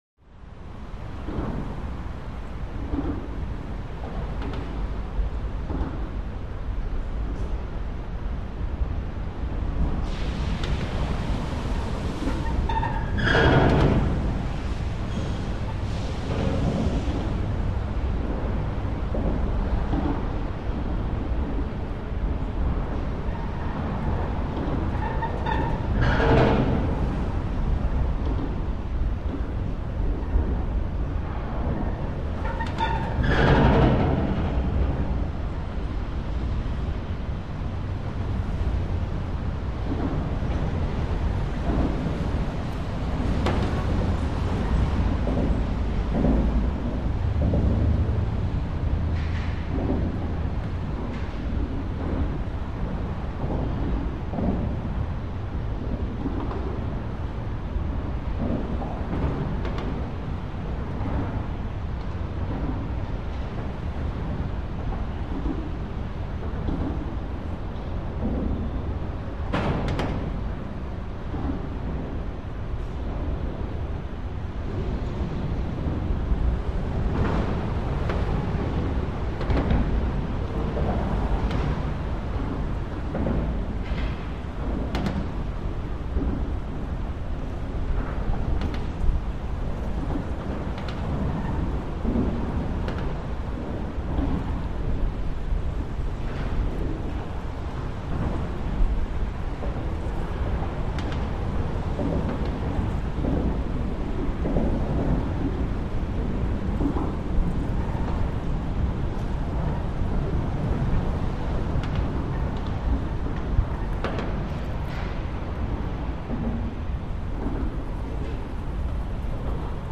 Heavy Traffic, Under Solid Bridge Point Of View. More Low End Rumble, Not As Whooshy As Track 1005-02. Rhythmic Tire Clunks, Some Suspension Squeaks, Truck Bys.